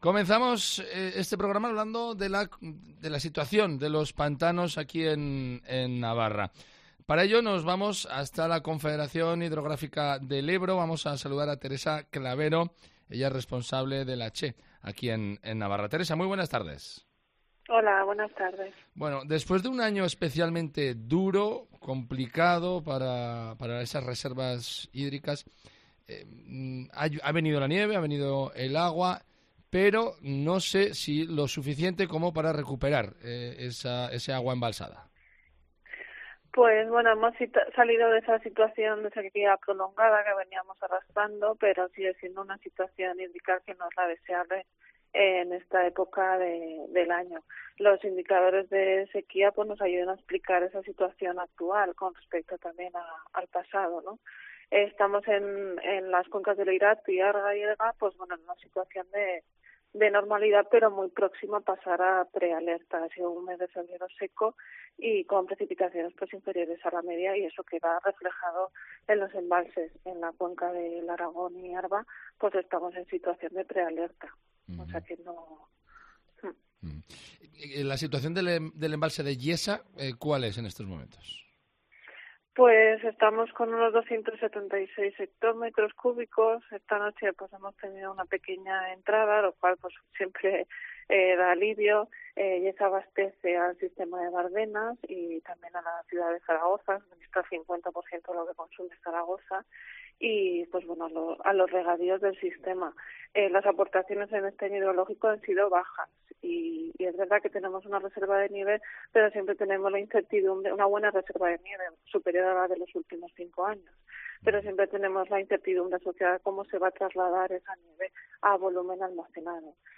ha contado en los micrófonos de Cope Navarra la situación actual de los embalses navarros